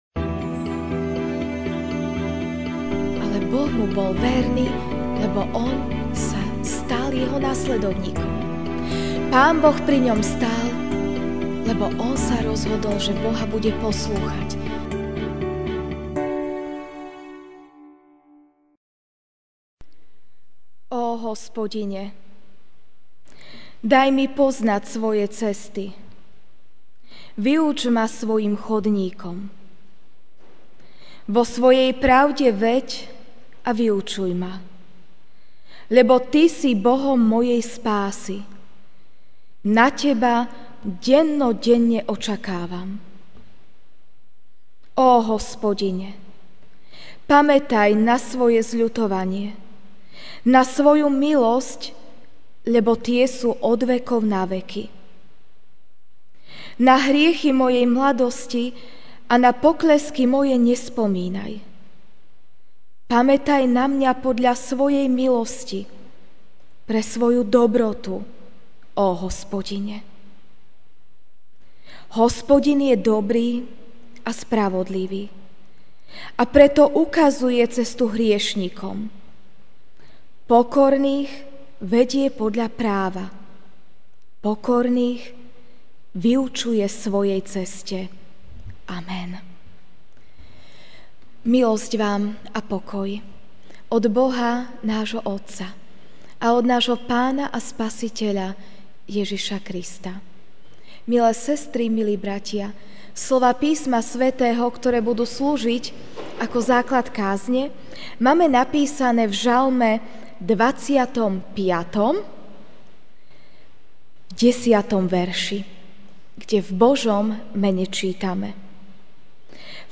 feb 12, 2017 Nezaslúžená milosť MP3 SUBSCRIBE on iTunes(Podcast) Notes Sermons in this Series Večerná kázeň: Nezaslúžená milosť (Žalm 25, 10) Všetky chodníky Hospodinove sú milosť a vernosť pre tých, ktorí zachovávajú Jeho zmluvu a Jeho svedectvá.